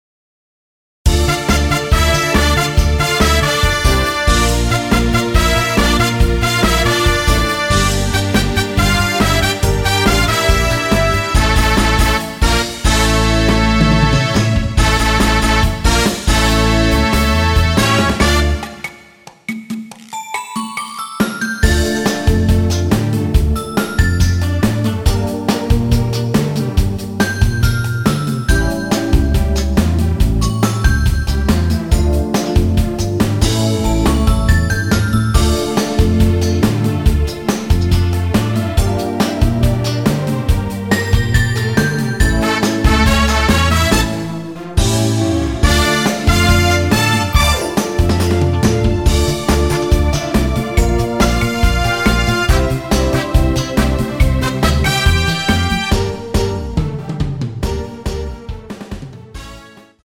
Dm
앞부분30초, 뒷부분30초씩 편집해서 올려 드리고 있습니다.
중간에 음이 끈어지고 다시 나오는 이유는